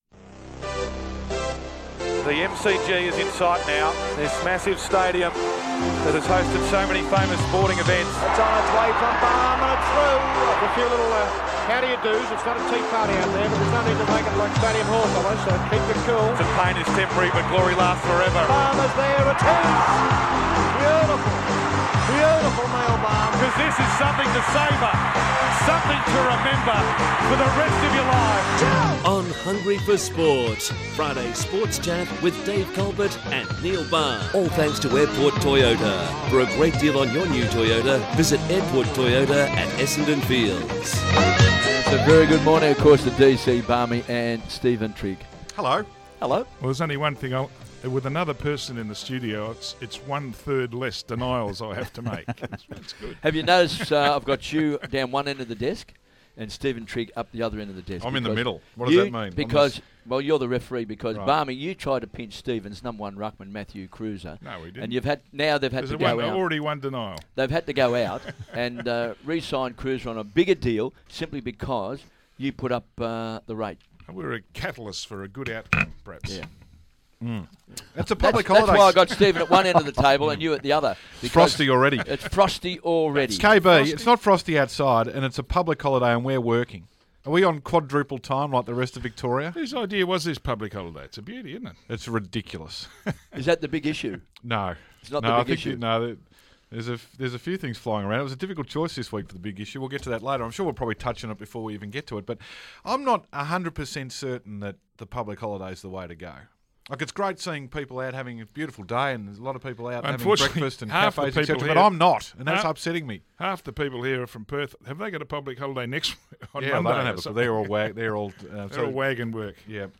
speaks to SEN's Hungry for Sport about the "reset" at the Blues and the new coaching panel.